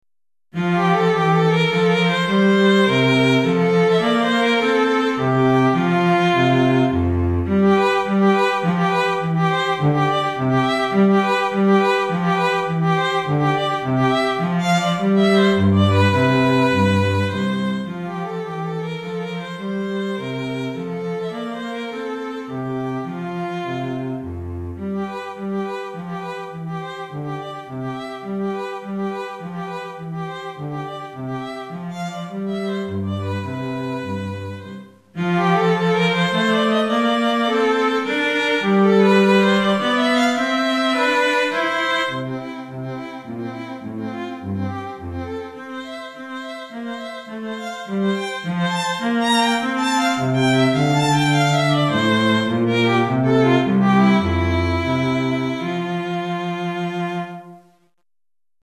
Violon et Violoncelle